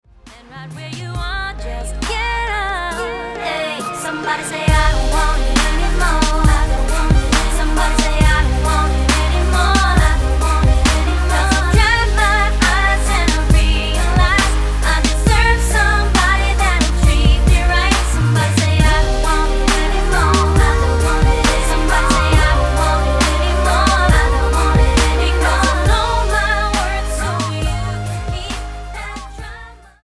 NOTE: Background Tracks 10 Thru 18